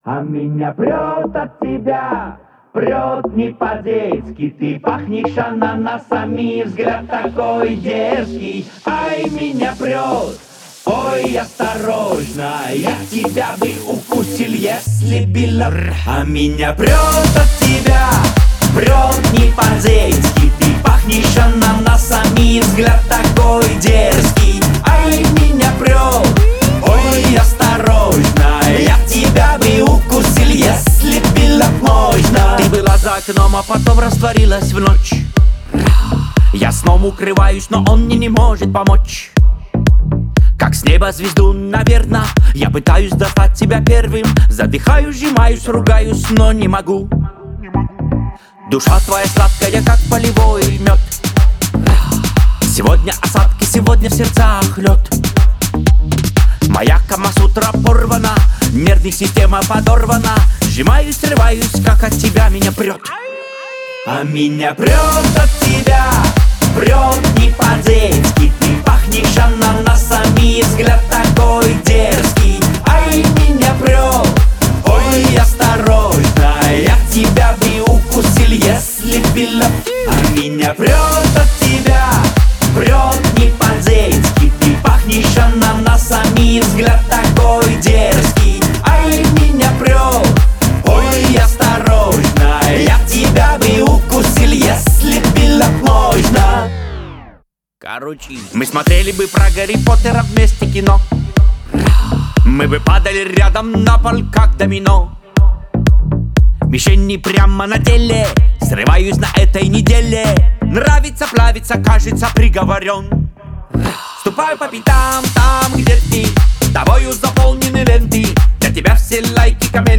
это зажигательный трек в жанре поп